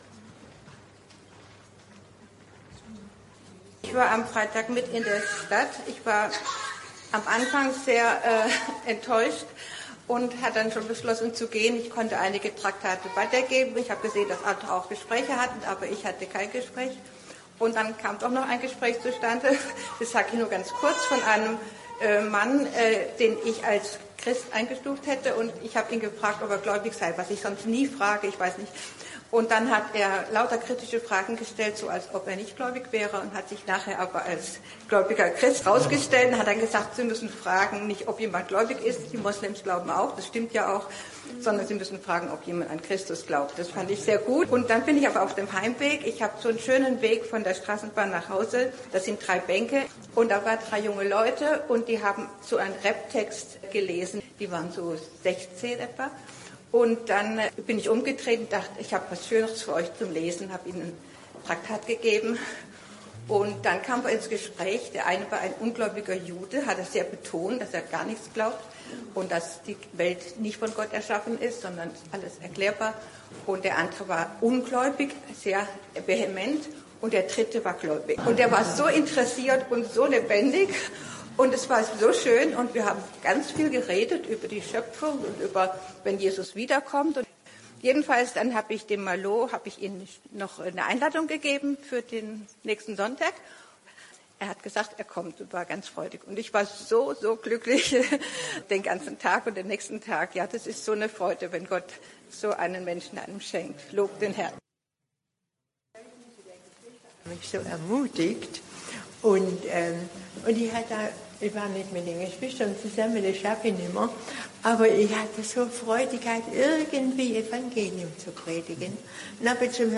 Gemeindeversammlung